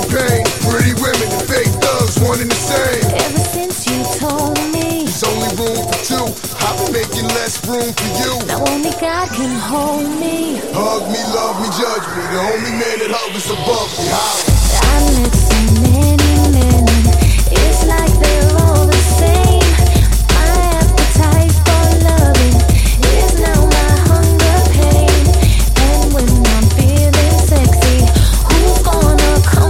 TOP > Vocal Track